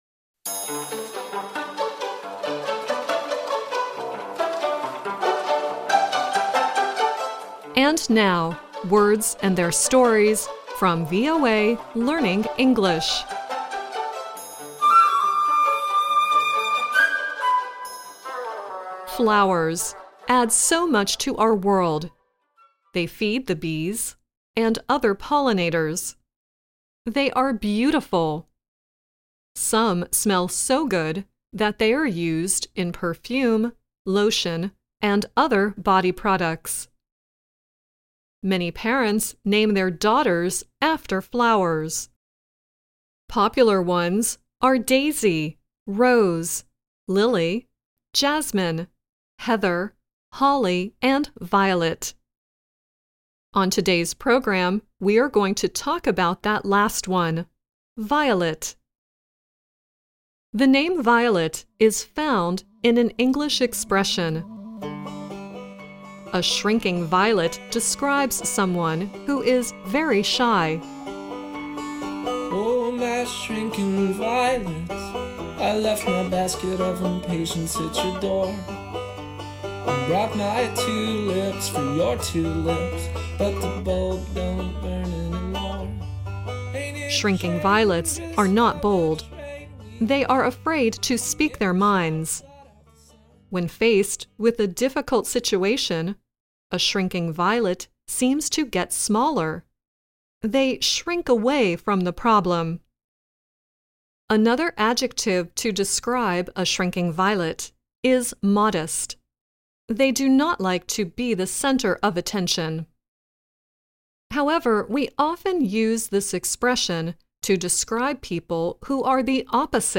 The song at the end is Diana Krall singing "Wallflower."